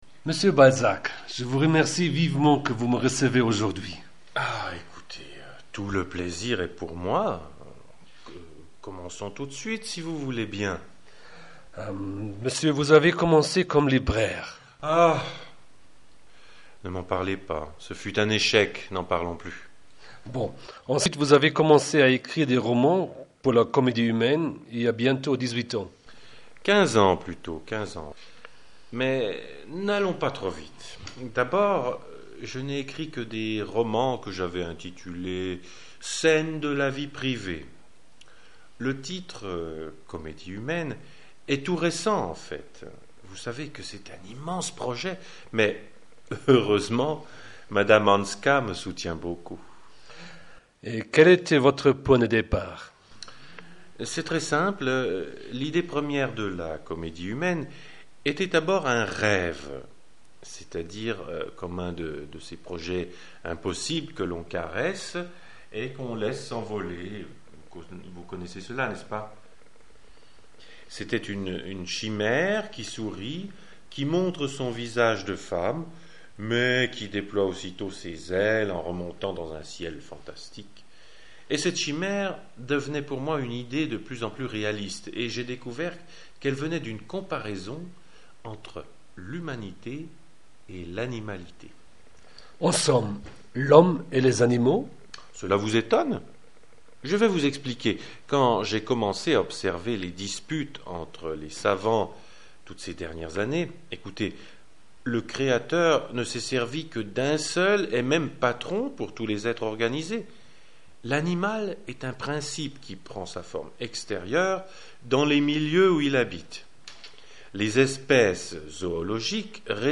Ein Interview mit dem Autor der Comédie humaine!
balzac-interview.mp3